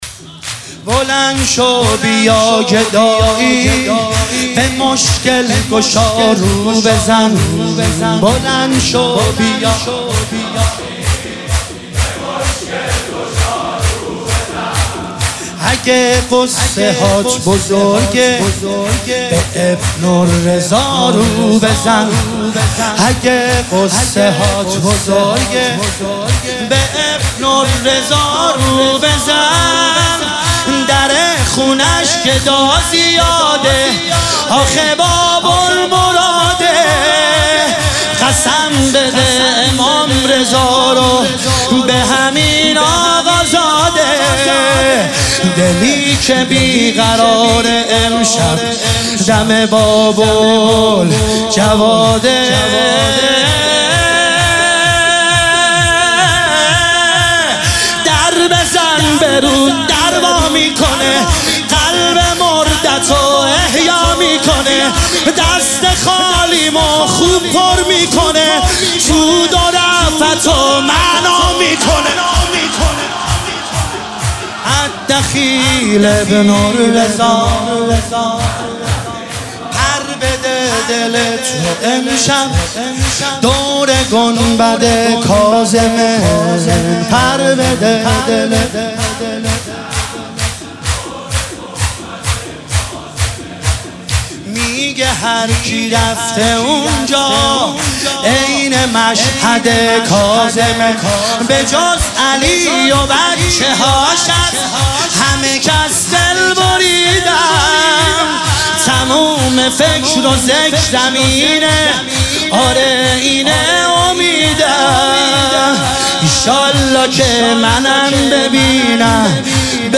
دانلود فایل صوتی نوحه شور شهادت امام جواد
شهادت امام جواد (ع) 1404